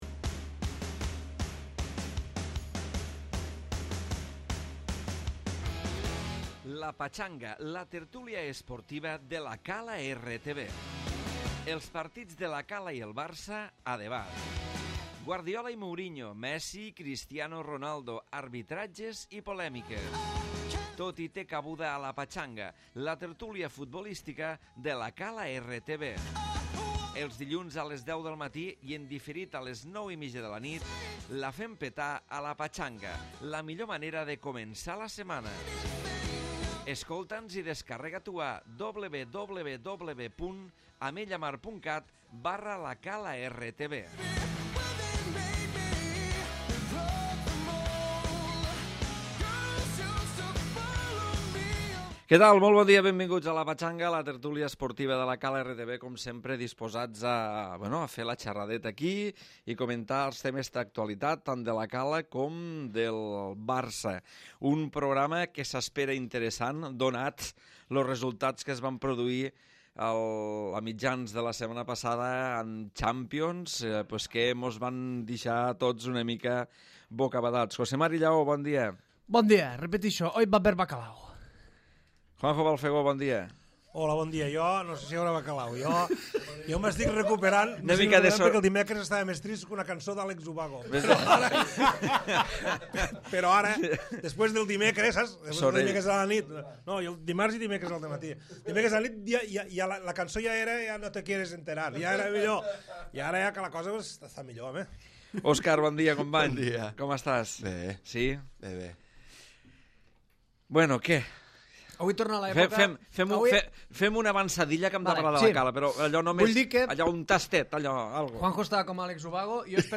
Tertúlia de futbol